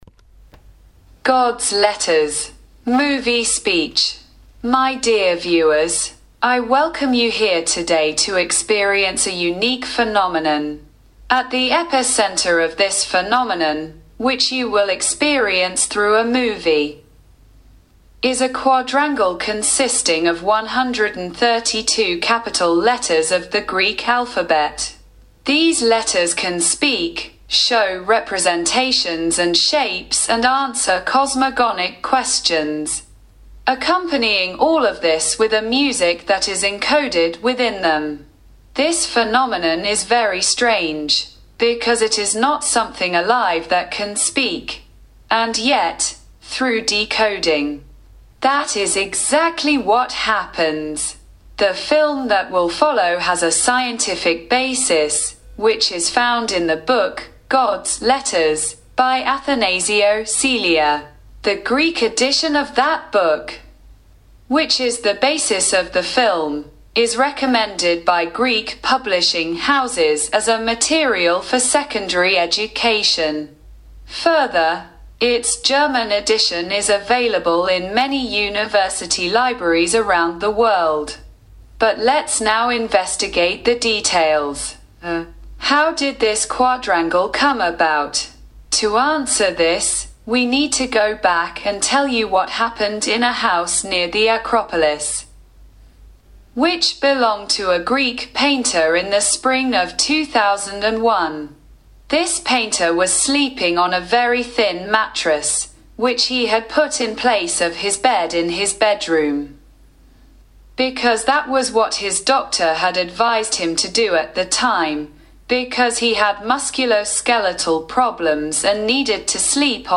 Movie Speech.MP3